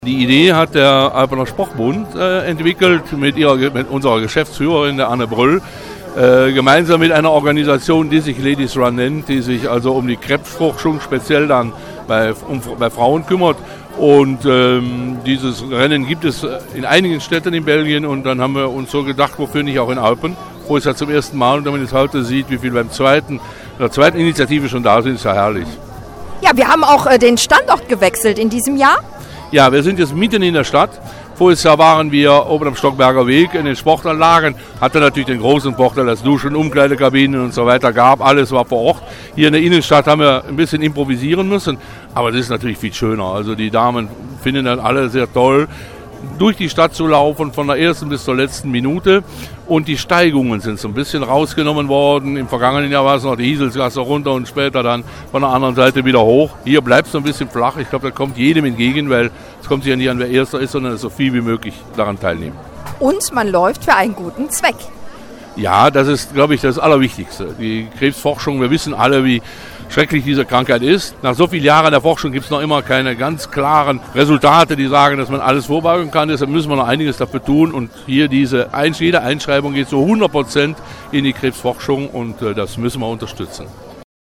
Unter dem Motto BeActive ging gestern die Europäische Woche des Sports an den Start. Auftaktveranstaltung war der Ladies Run in Eupens Innenstatdt.